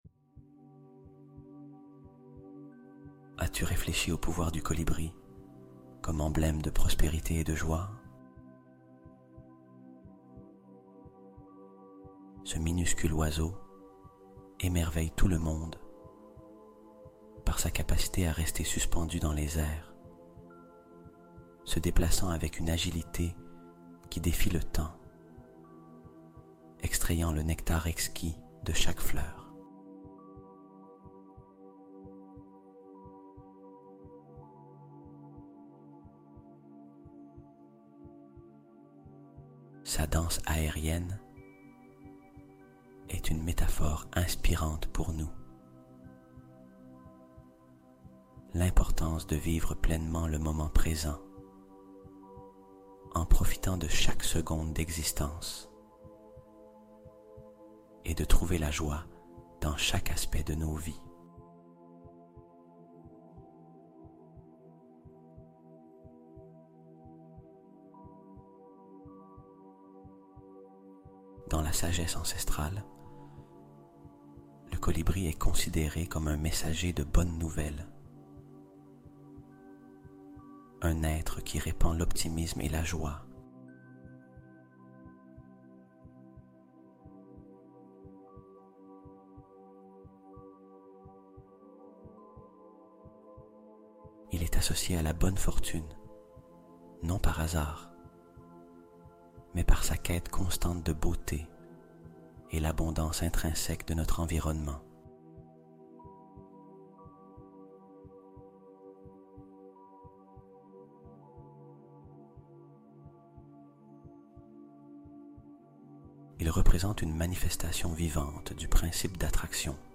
Harmonie Sonore : Fréquences apaisantes pour attirer le positif et la joie